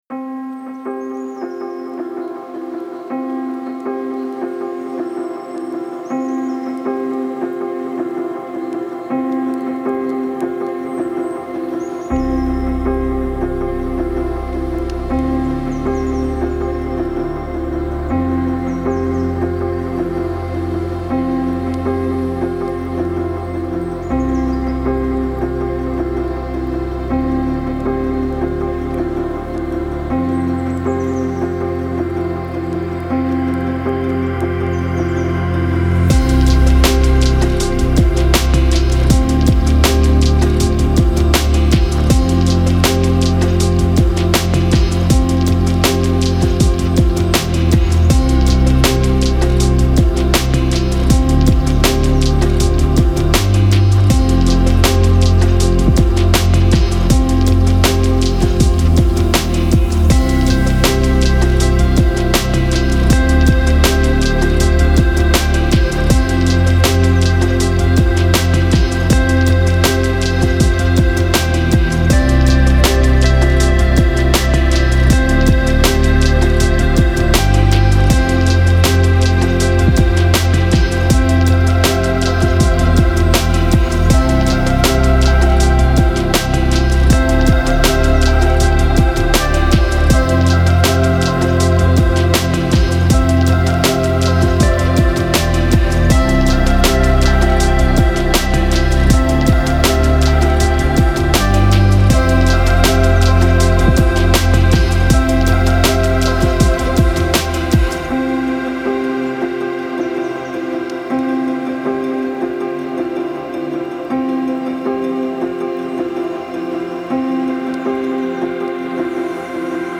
موسیقی بی کلام الکترونیک موسیقی بی کلام امید بخش